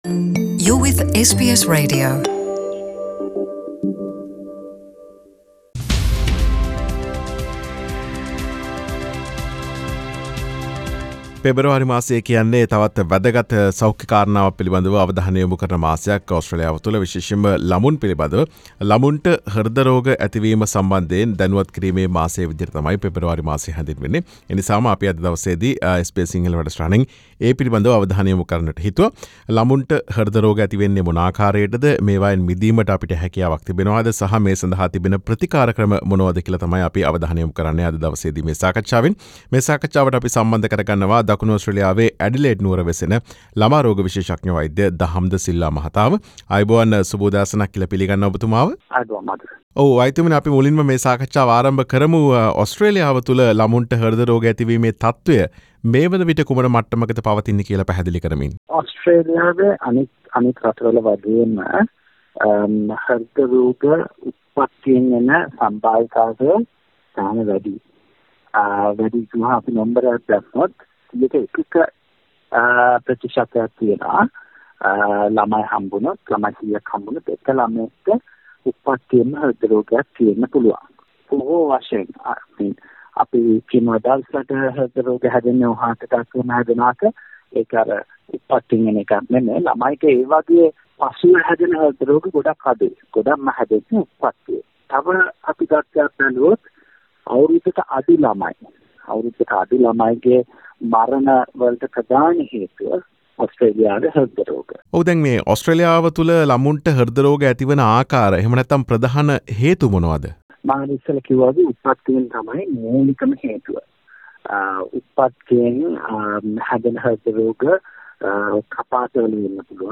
SBS සිංහල සිදුකළ සාකච්ඡාව.